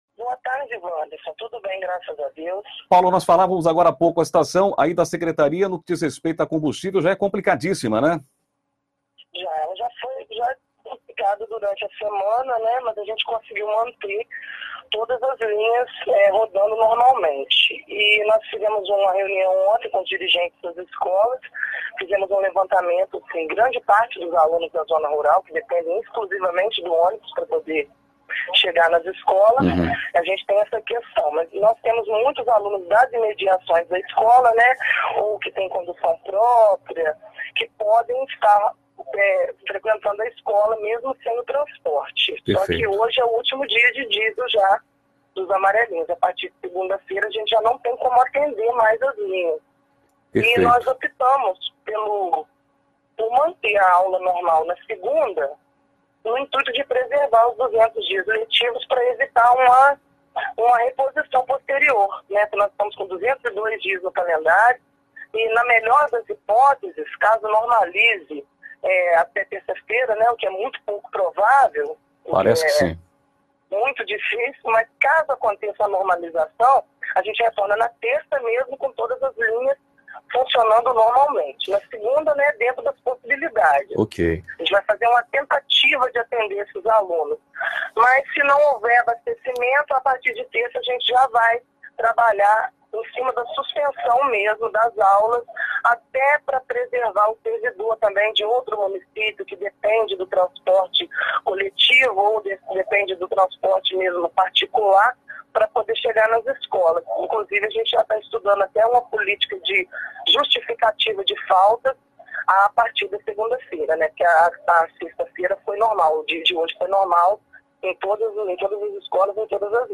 25 maio, 2018 ENTREVISTAS, NATIVIDADE AGORA